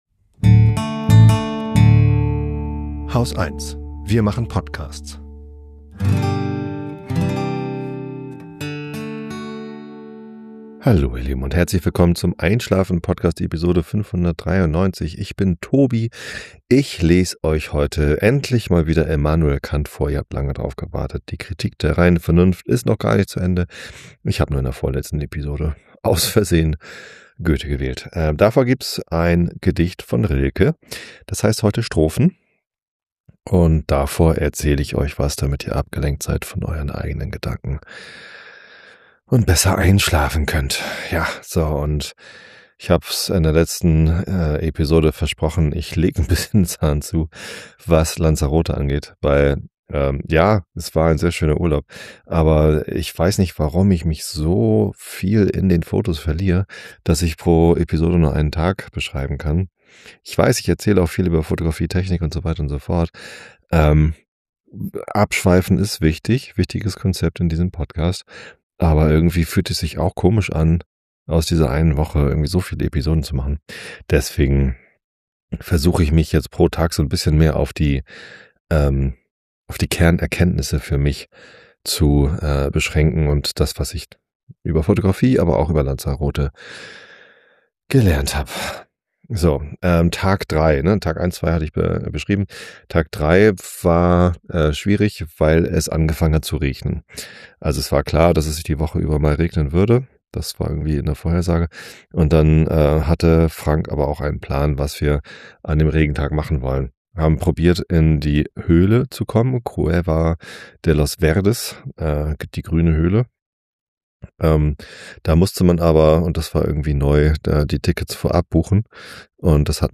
Monologe zum Entspannen